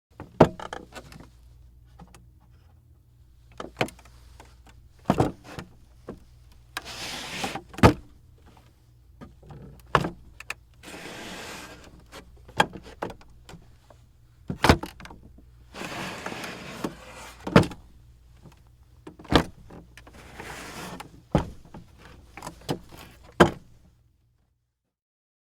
Zombie Footsteps
Zombie Footsteps is a free horror sound effect available for download in MP3 format.
yt_JSZ_3QSkToE_zombie_footsteps.mp3